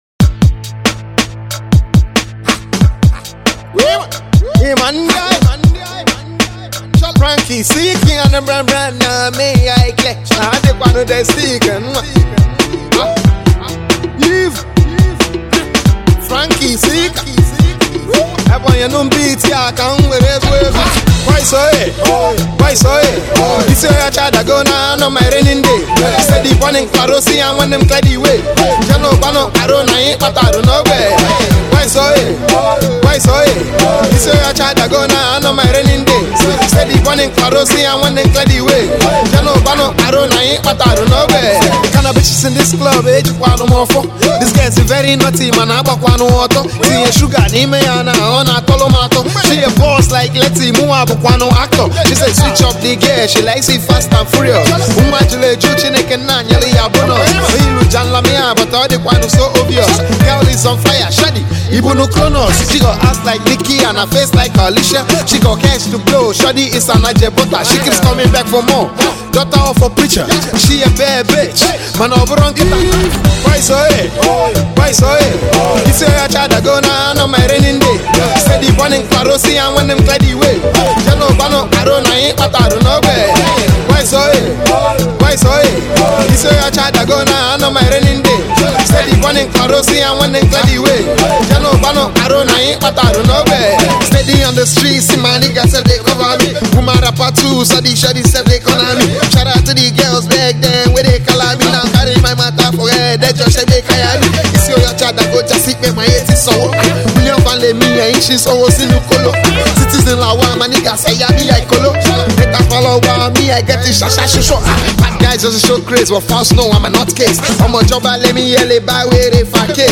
His out with a brand you street anthem.